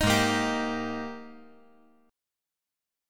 G#M7sus2 Chord
Listen to G#M7sus2 strummed